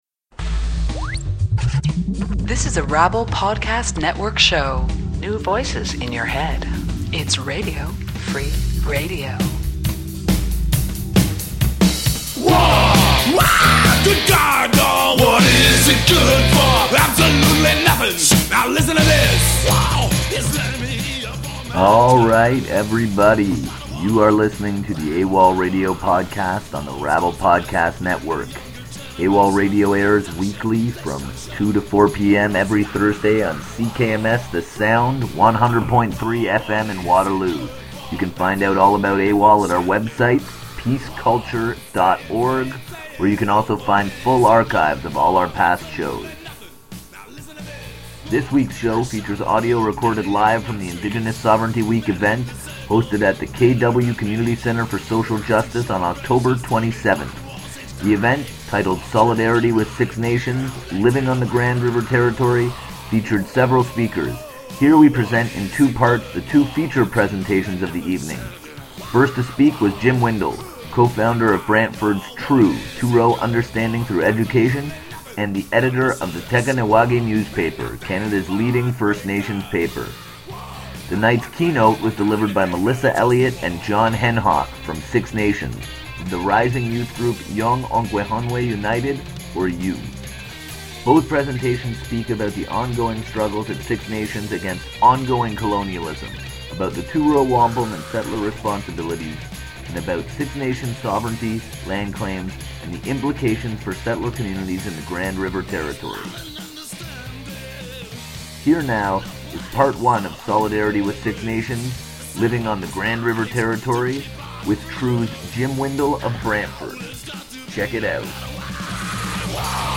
Recorded live at the Kitchener-Waterloo Community Centre for Social Justice, Oct. 27.